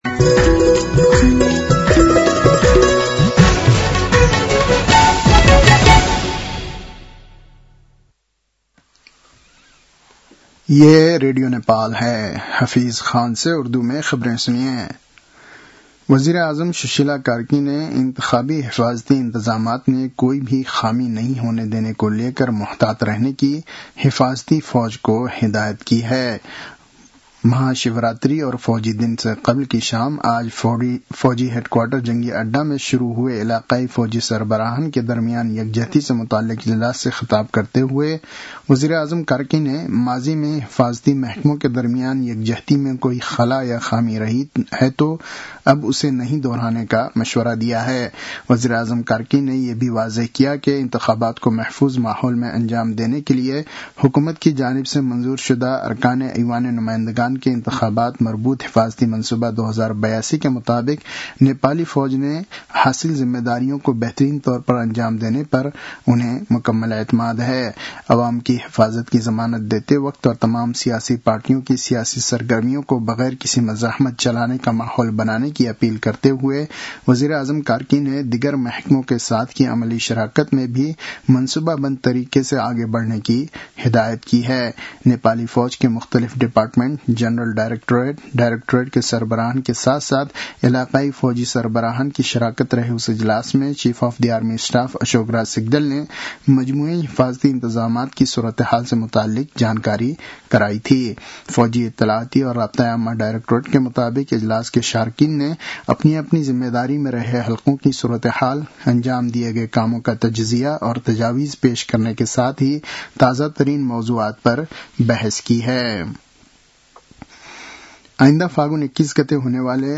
उर्दु भाषामा समाचार : २७ माघ , २०८२